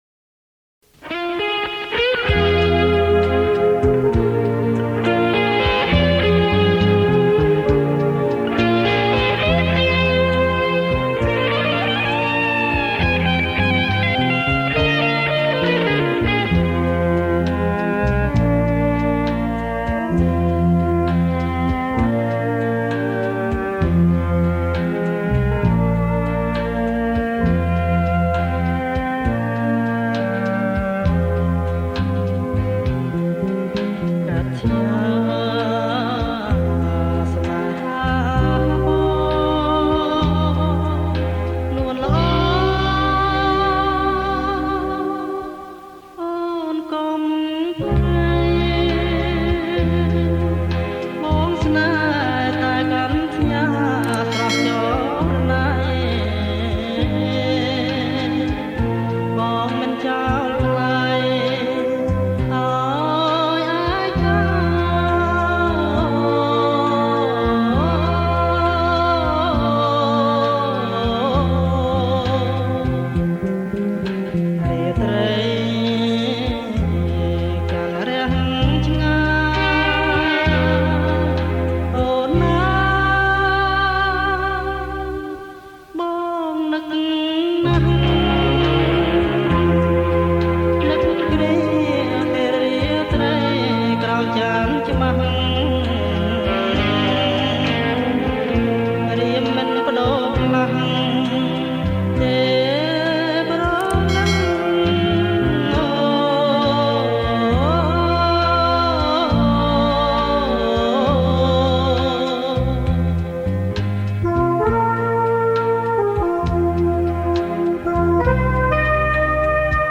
ប្រគំជាចង្វាក់ Slow rock